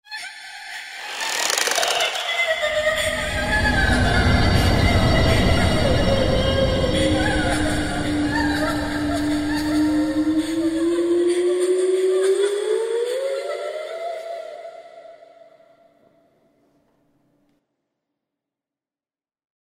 Sound Effect Horror Intro 2.mp3